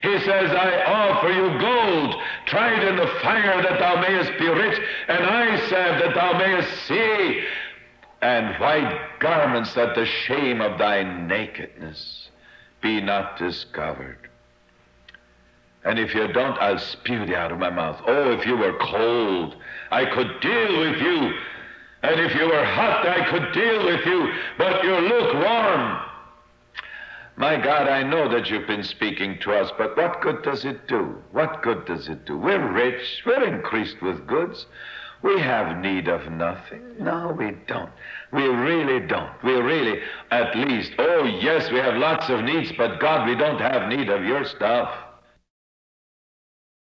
Audio Quality: Poor
A few pauses were shortened, and stutters removed.
Reel to reel tape RPCA0028 seems to contain the original for this talk, although the quality seems not to be noticably better than the cassette master.